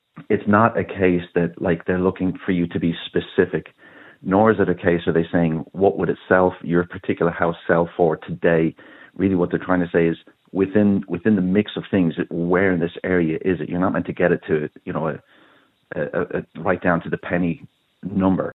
Financial Advisor